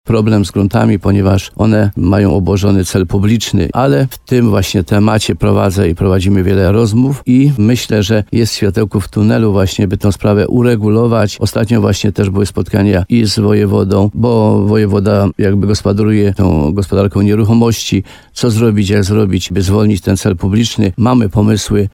Starosta limanowski Mieczysław Uryga zapewniał w rozmowie z RDN Nowy Sącz, że sytuacja się zmieniła.